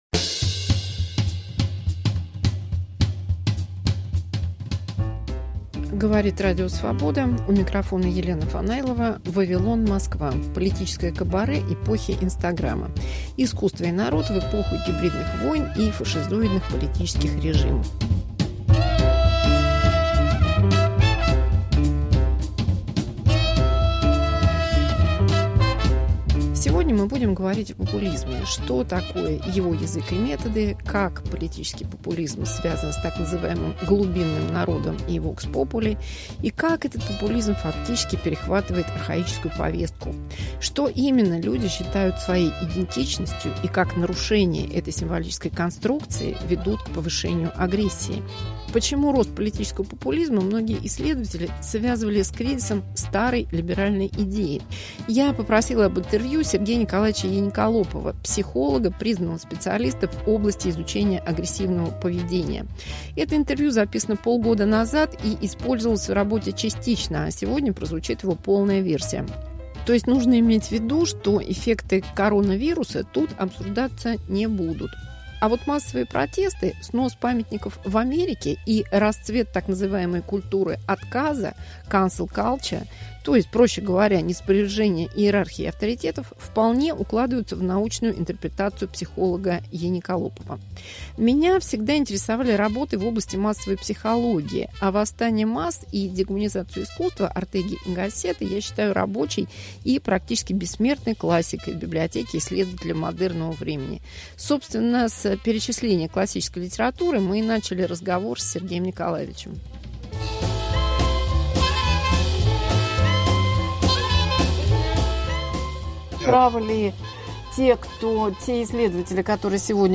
Игры популистов. Интервью